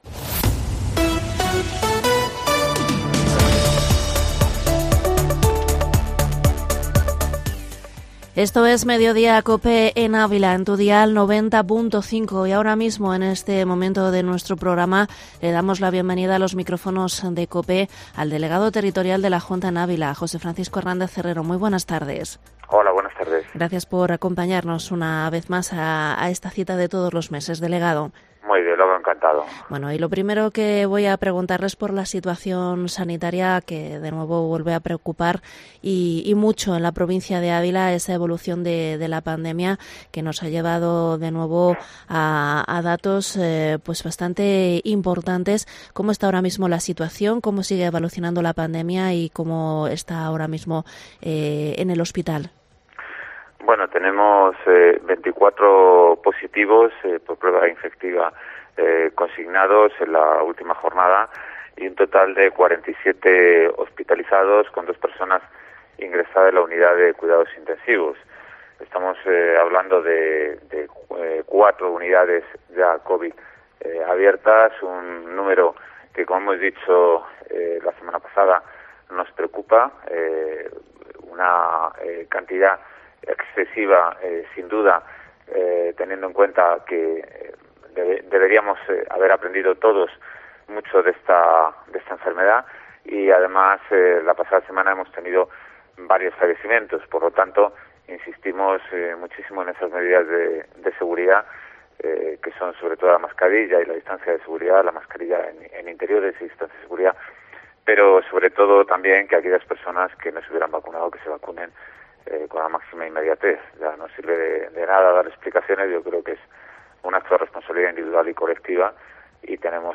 Entrevista en Mediodía Cope al delegado territorial de la Junta 29 de noviembre